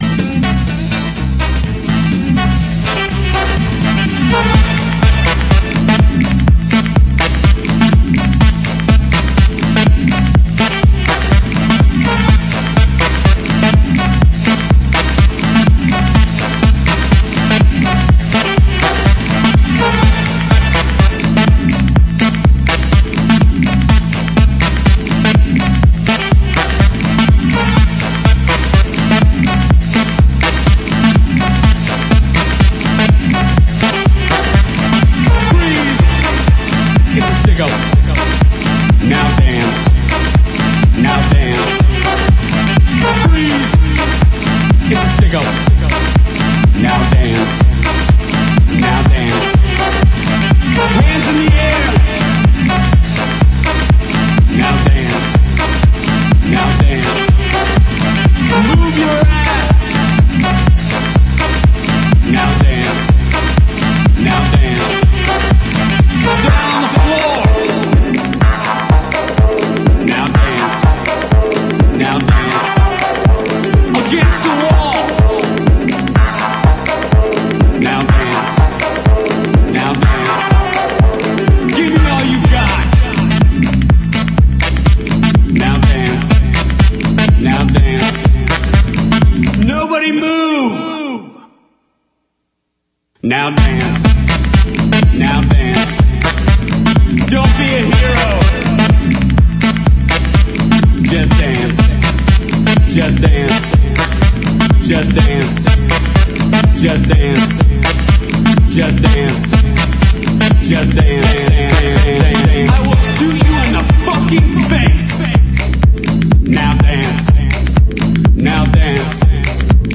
G-Swing
House
フロアーユースな強力スウィング・ハウス5トラックス!